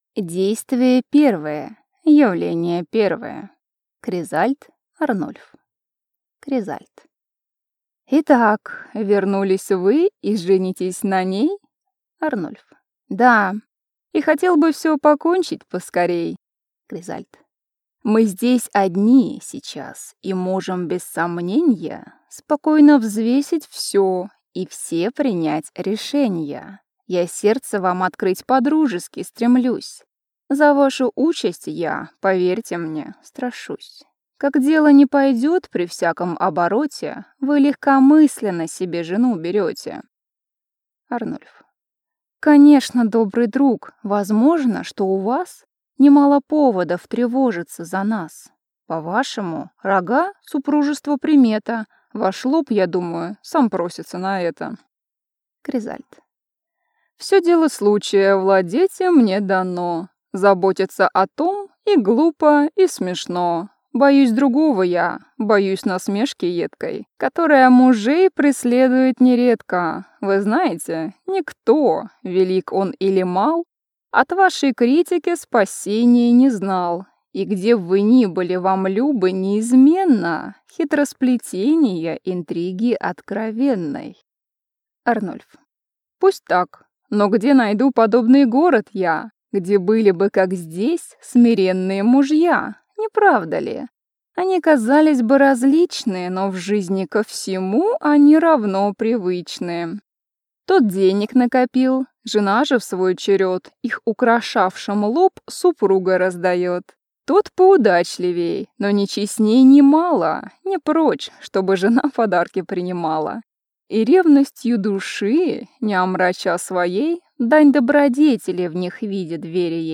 Аудиокнига Школа жен | Библиотека аудиокниг